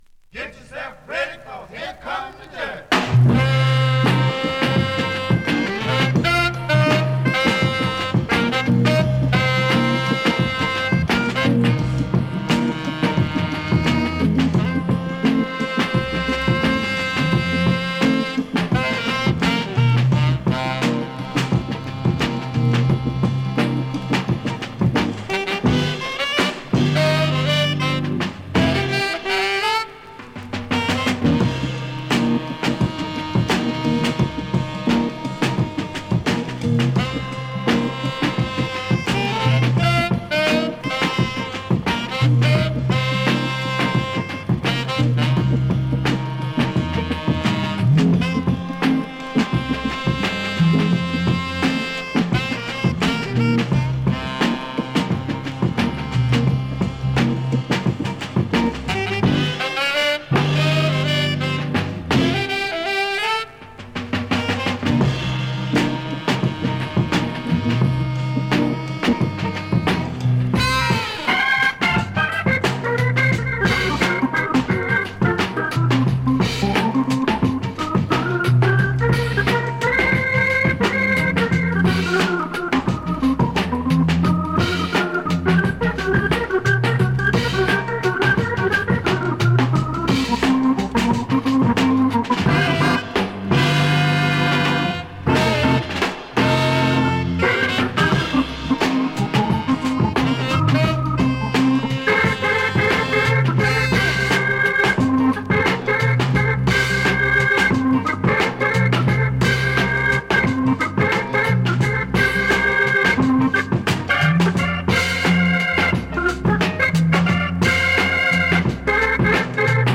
SOUL、FUNK、JAZZのオリジナルアナログ盤専門店